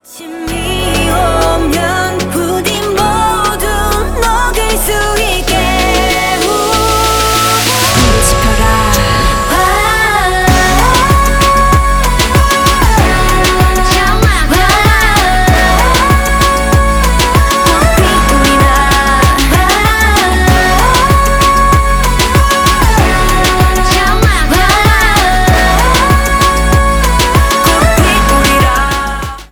Stereo
Поп